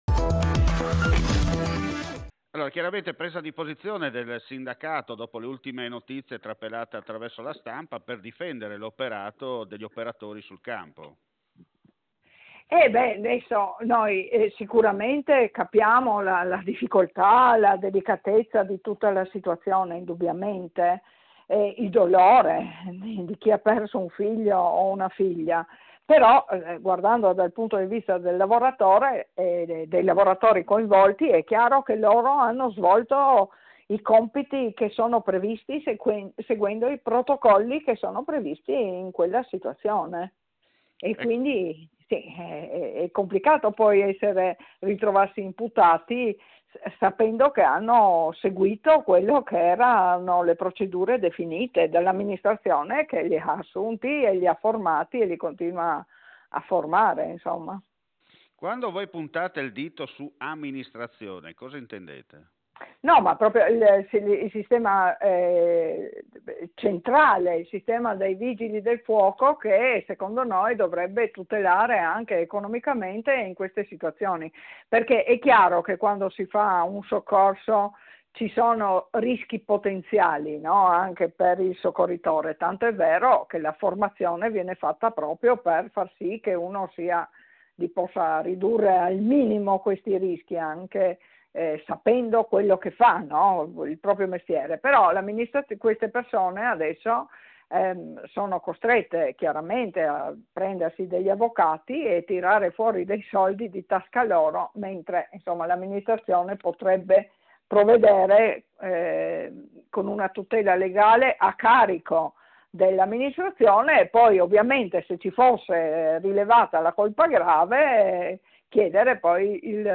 L’AUDIO INTERVISTA